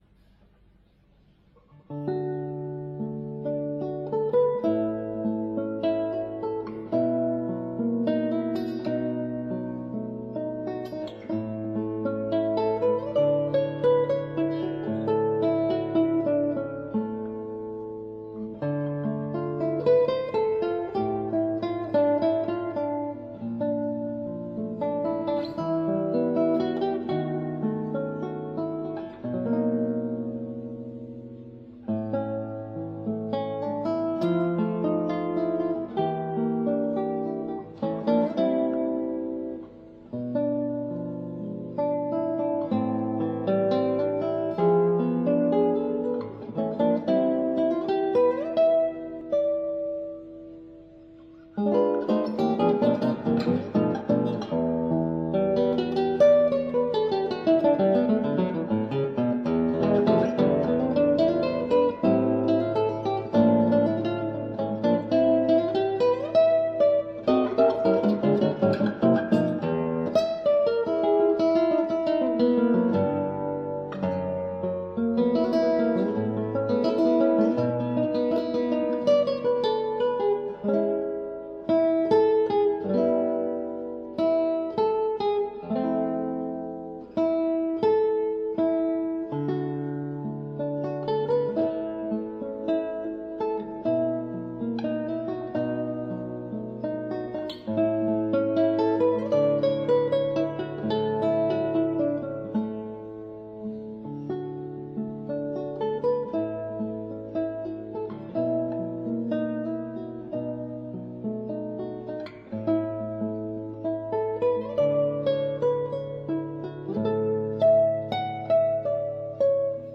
KOMPOSITIONEN FÜR  GITARRE  SOLO